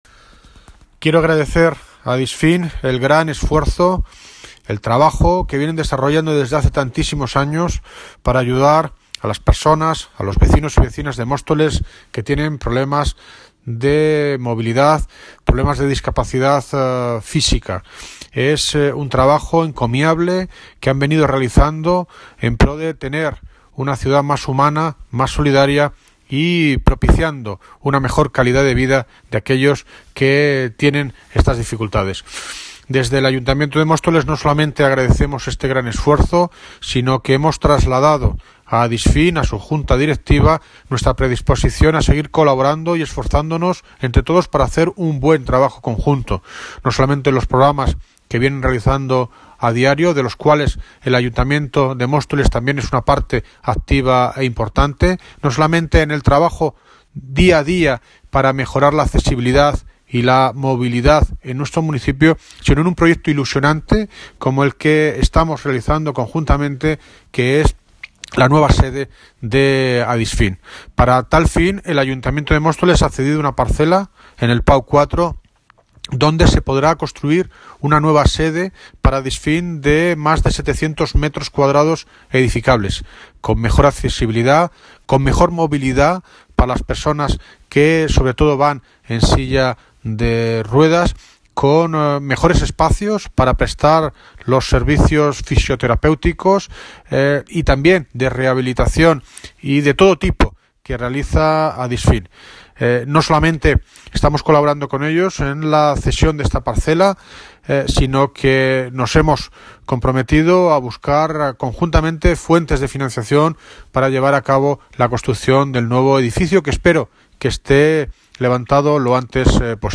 Audio - David Lucas (Alcalde de Móstoles) Sobre visita la sede de ADISFIM